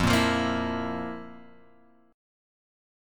F Major 7th Suspended 4th
FM7sus4 chord {1 1 3 3 x 0} chord